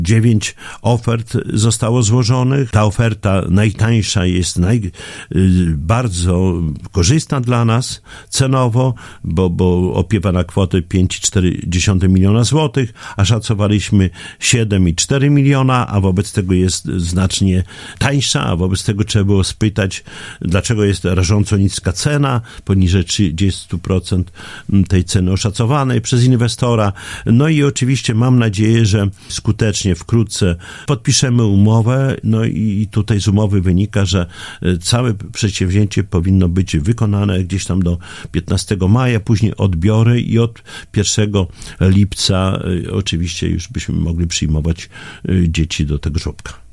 O szczegółach Czesłąw Renkiewicz, prezydent Suwałk.